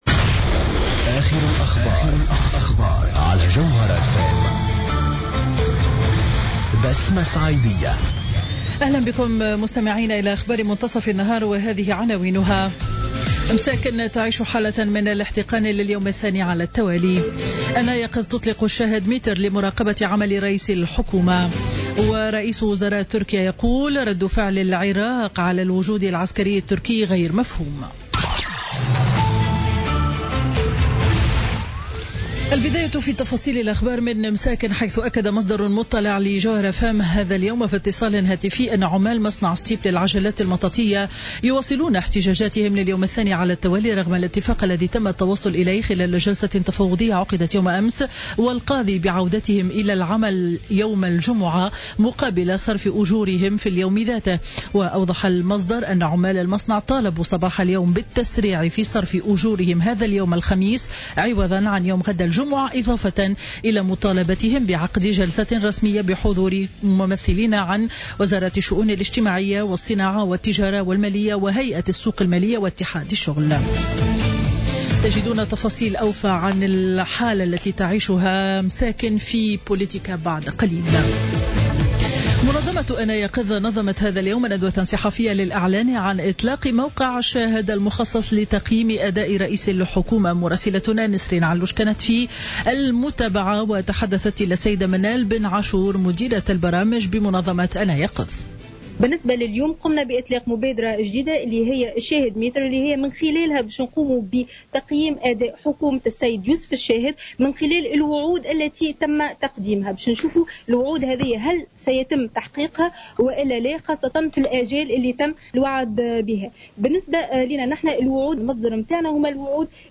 نشرة أخبار منتصف النهار ليوم الخميس 6 أكتوبر 2016